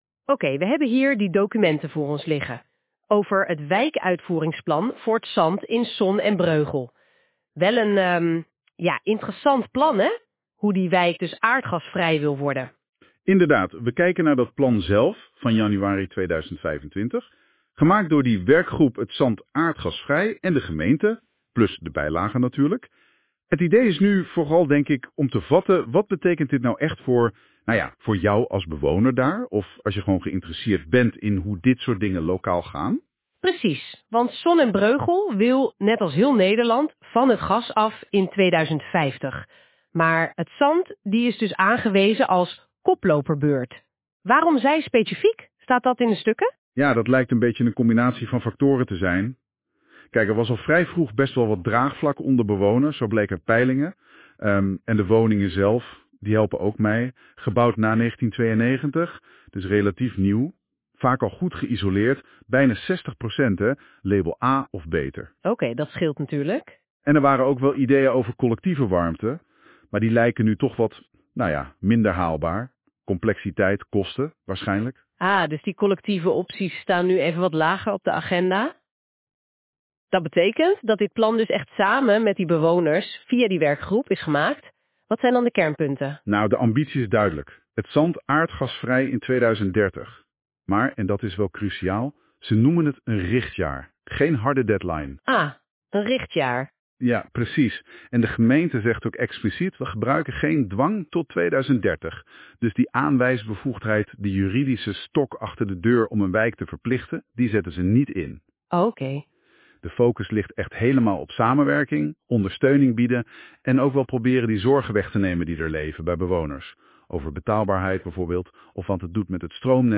Er is (met AI!) een